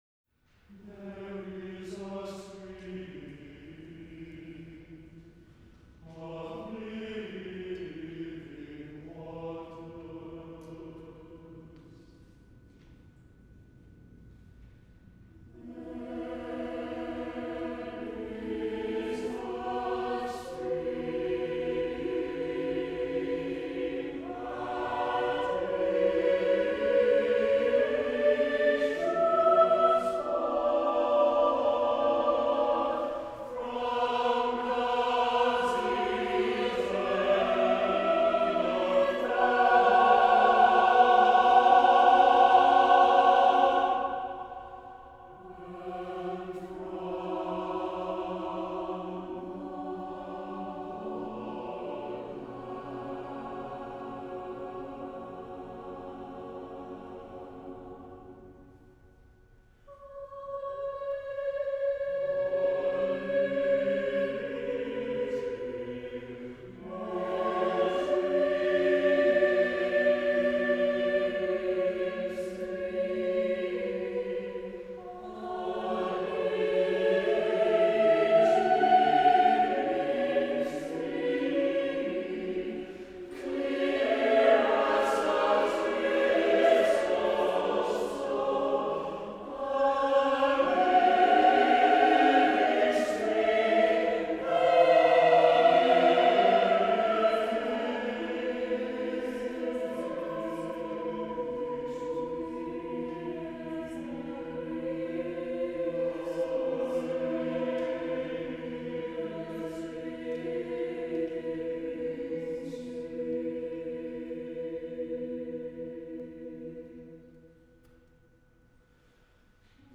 Choral Ensembles
Peter Hallock: There is a stream, recorded live by the University of Washington Recital Choir, March 2006